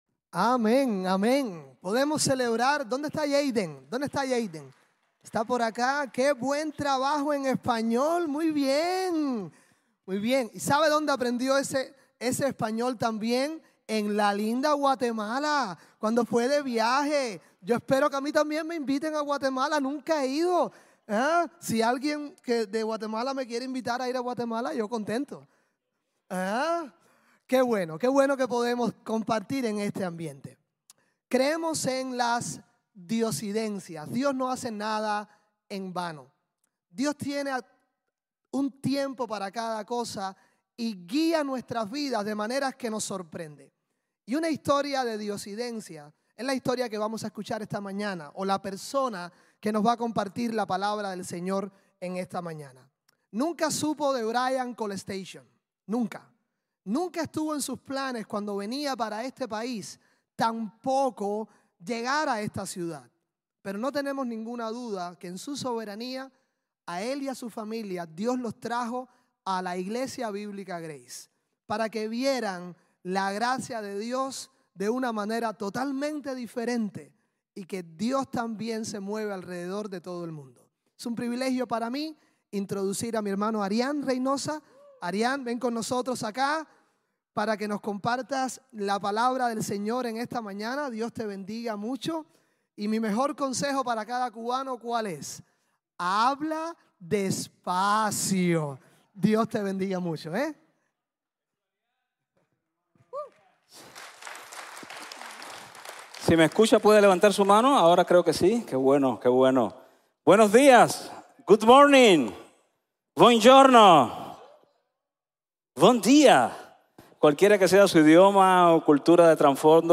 Cree y Ve | Sermón | Grace Bible Church